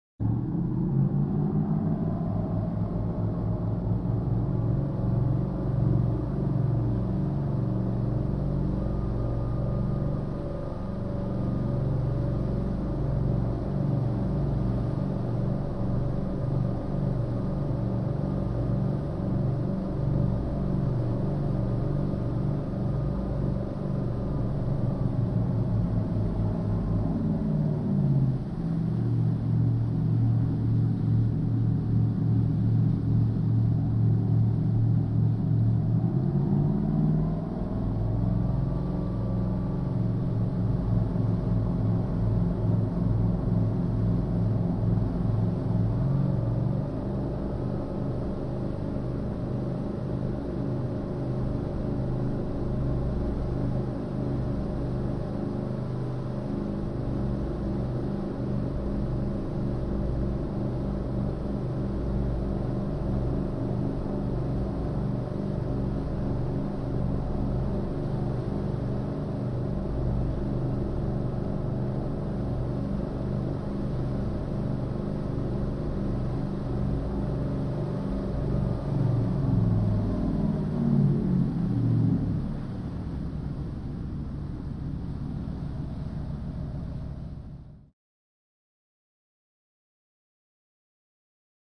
Power Yacht 1; Variable Steady, Few Accelerations And Decelerations With Slight Bow Wash. Large Twin.